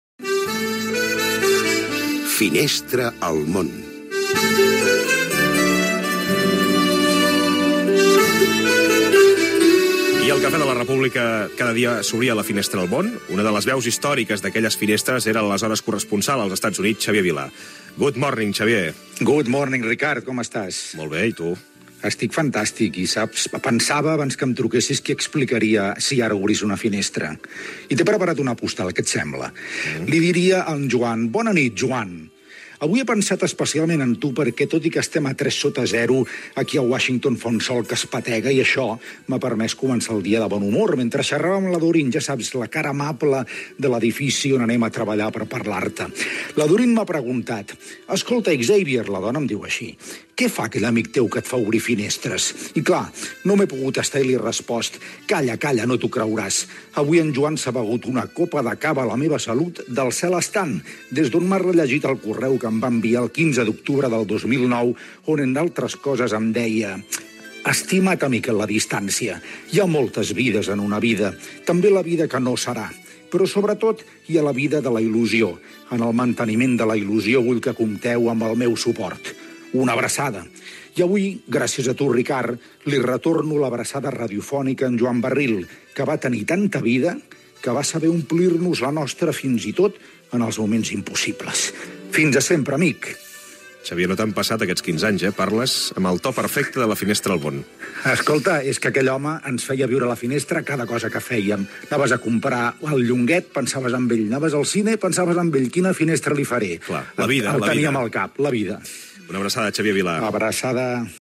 "La finestra al món" amb una postal sonora dedicada a Joan Barril, escrita des de Washington, en el desè aniversari de la seva mort.
Info-entreteniment